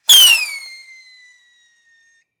rocketfly.ogg